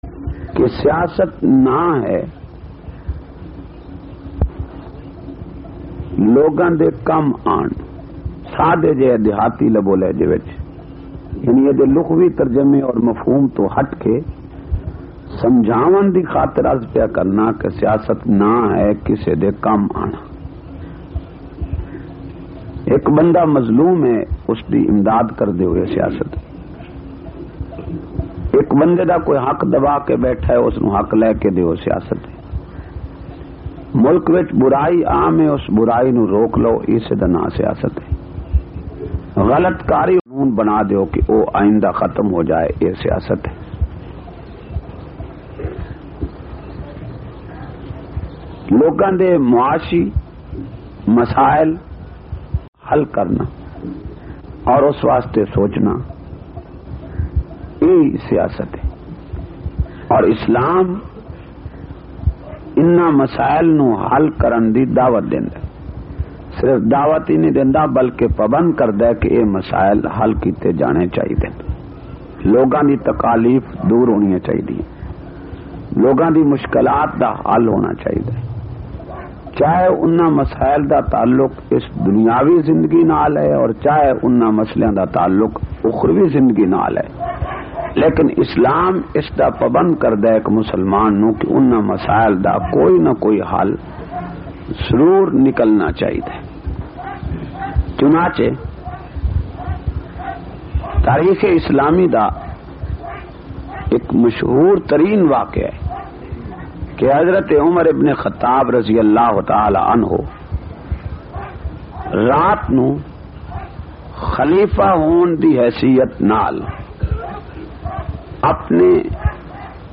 254- Islami Siyasat Aur Shan e Farooq e Azam Siraiki Bayan.mp3